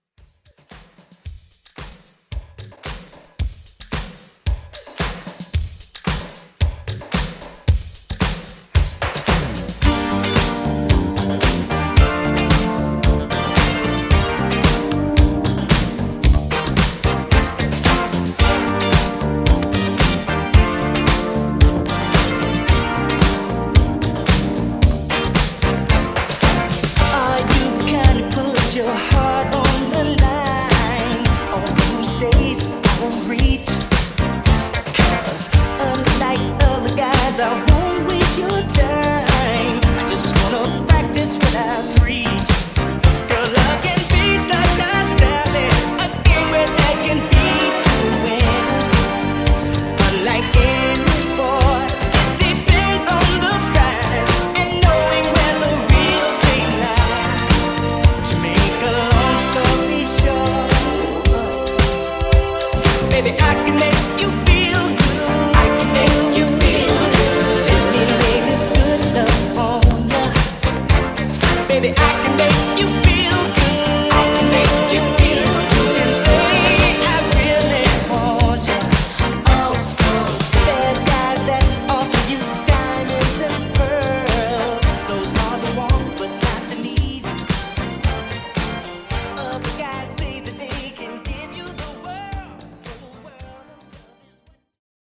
80’s Pop Redrum)Date Added